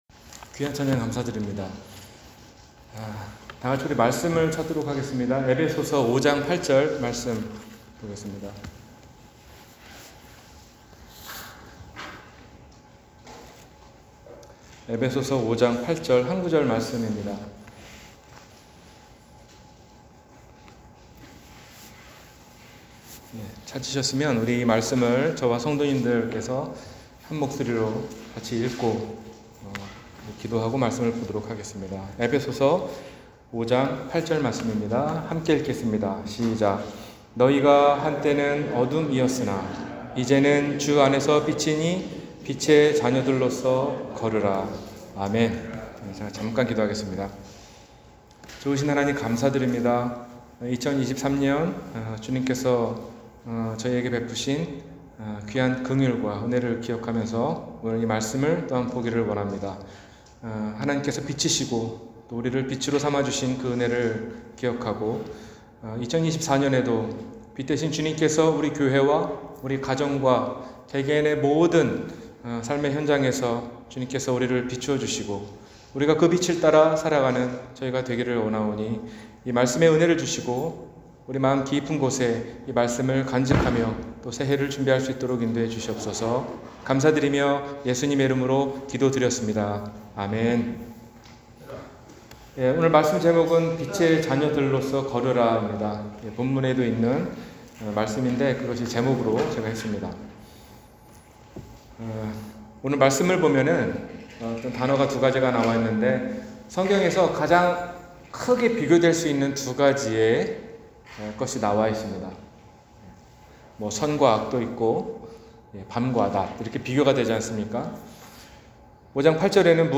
빛의 자녀들로 걸으라 -주일설교